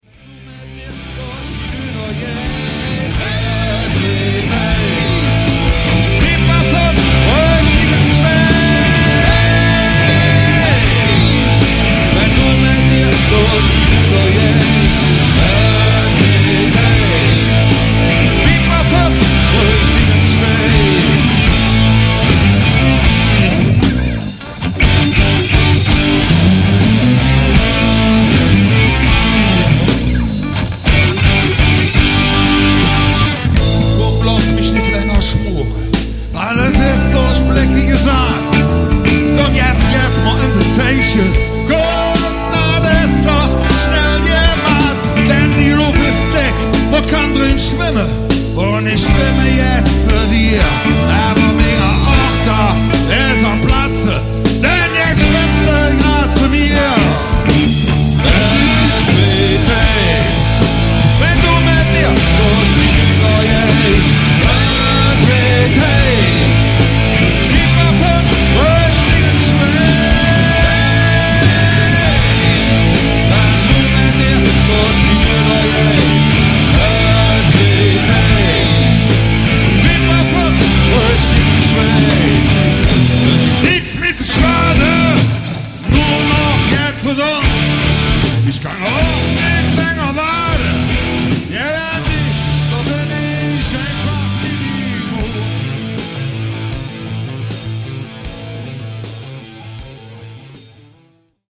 Rockband mit ausschließlich kölschen Texten